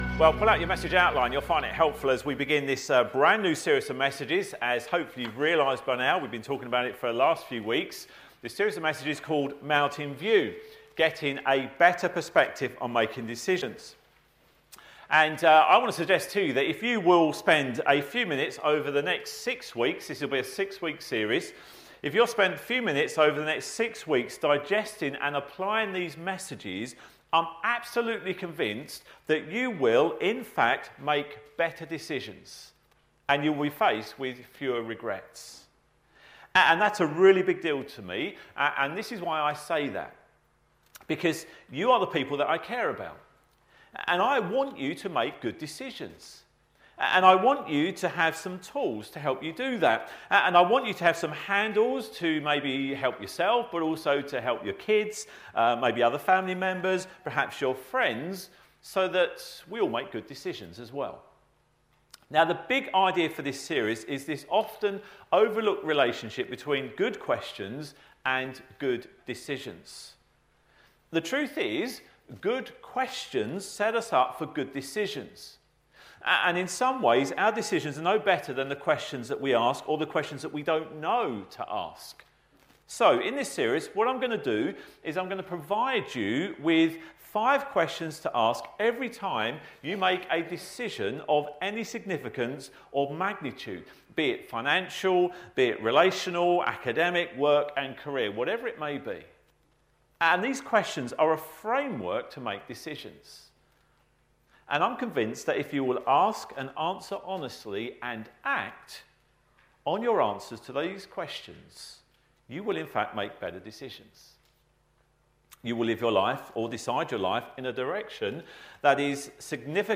In our new series of messages, we will look at how to get the bigger perspective in life so that we can make right decisions. In this sermon, for the start of this new series, we look at questions to ask every time we make a decision of any significance or magnitude be it financial, relational, academic, or work and career.